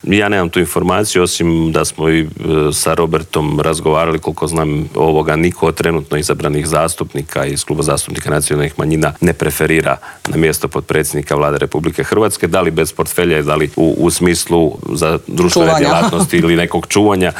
ZAGREB - Nakon što je predsjednik HDZ-a Andrej Plenković predao 78 potpisa i od predsjednika Zorana Milanovića dobio mandat da treći put zaredom sastavi Vladu, saborski zastupnik češke i slovačke nacionalne manjine Vladimir Bilek otkrio je u Intervjuu tjedna Media servisa tko je od manjinaca dao svoj potpis.